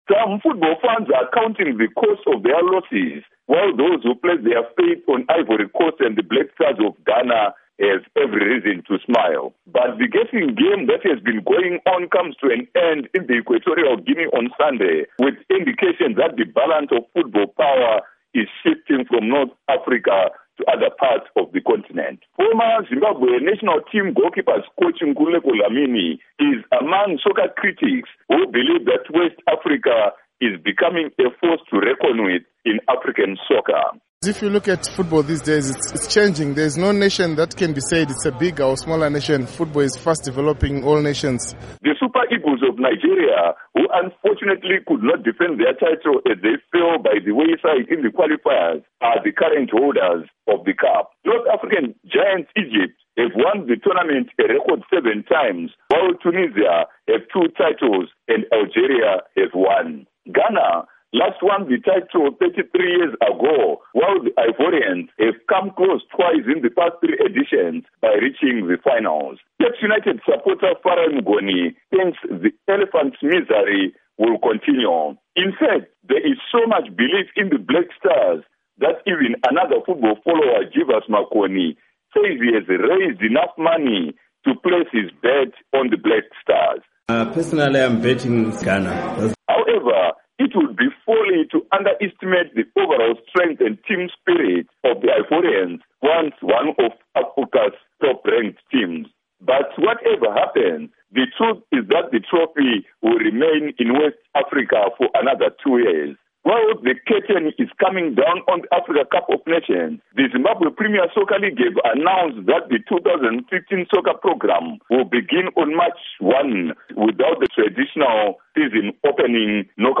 Report on African Cup of Nations Final